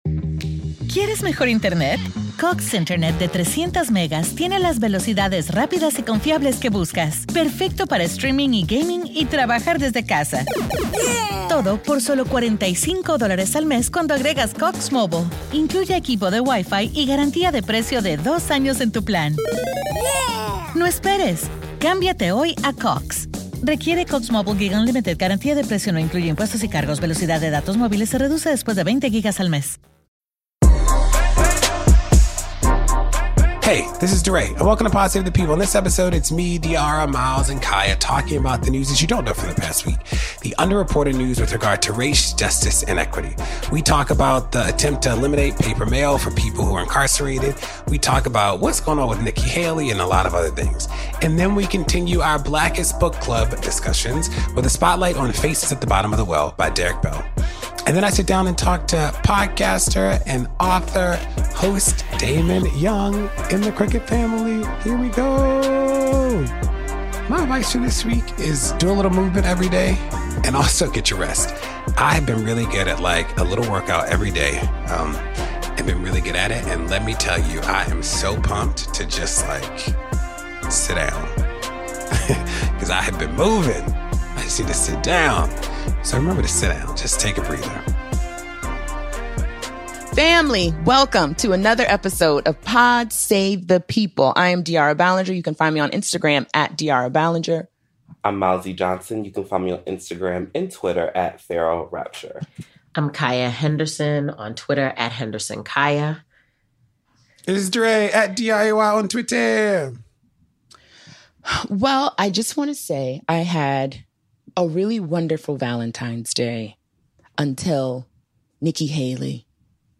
Pod Save the People continues Blackest Book Club programming with Faces at the Bottom of the Well by Derrick Bell. DeRay interviews author and host of Crooked's Stuck with Damon Young.